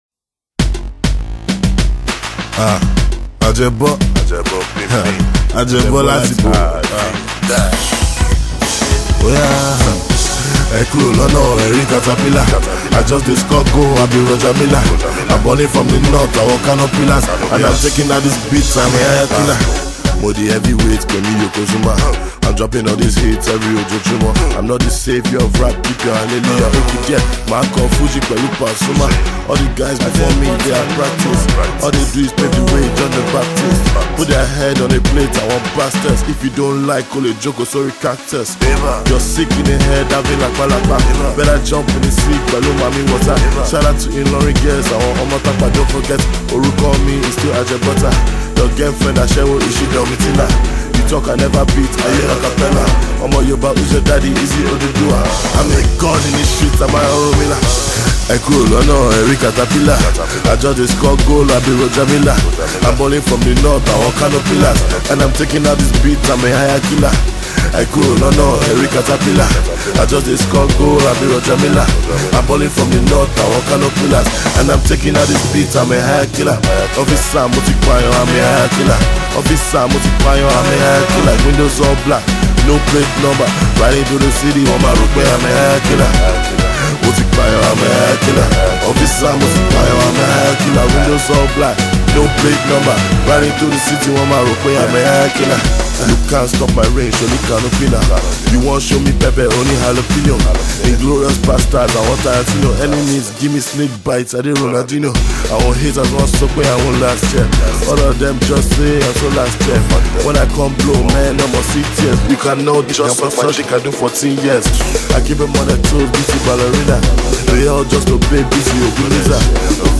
he flosses his skills as an Afro-Rap star.